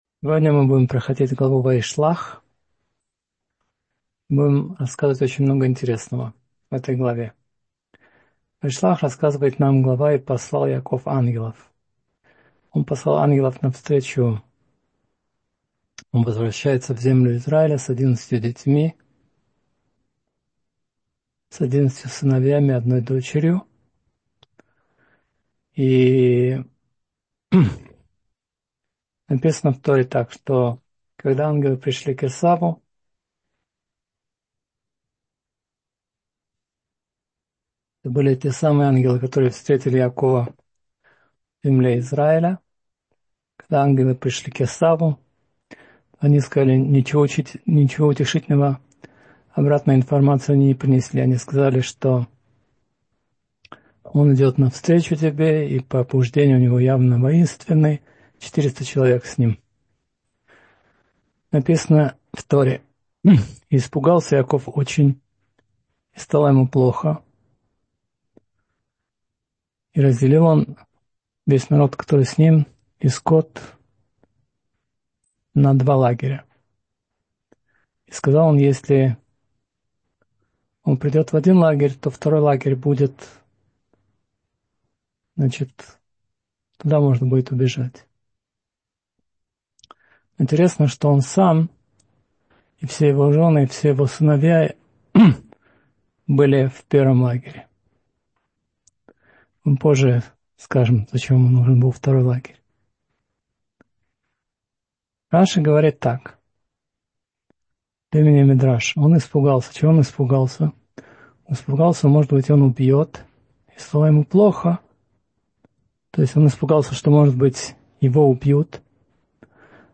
Ваишлах — слушать лекции раввинов онлайн | Еврейские аудиоуроки по теме «Еврейские законы» на Толдот.ру